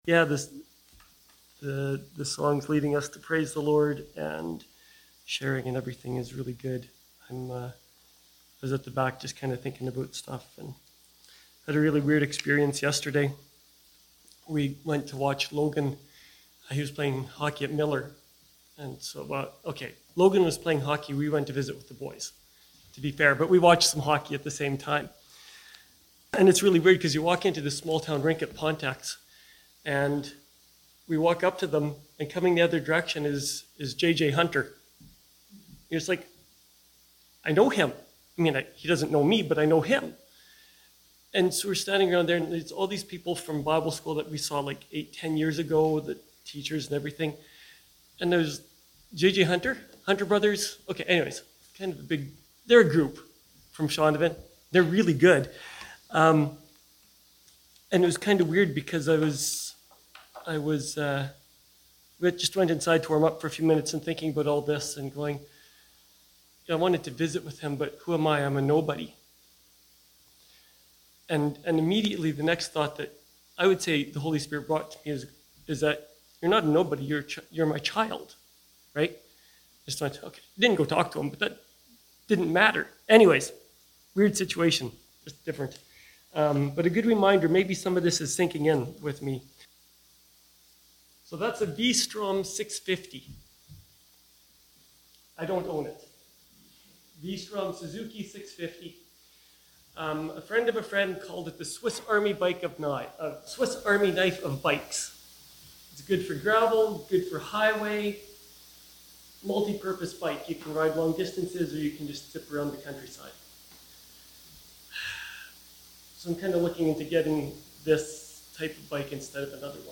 Praise and Worship